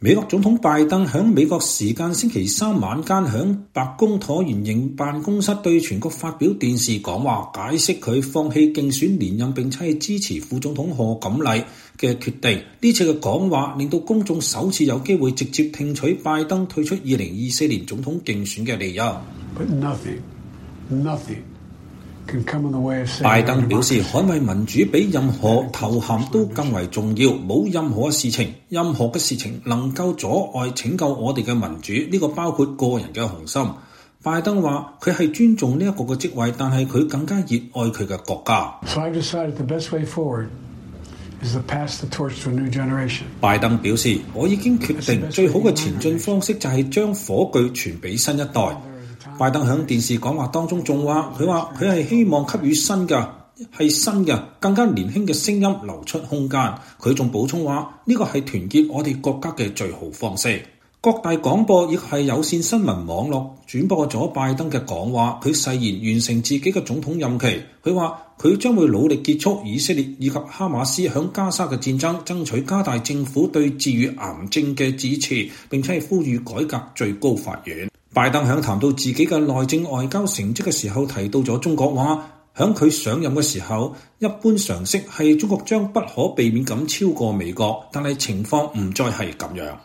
美國總統喬·拜登(Joe Biden)美國時間星期三(7月24日)晚間在白宮橢圓形辦公室對全國發表電視講話，解釋他放棄競選連任並支持副總統賀錦麗(Kamala Harris)的決定。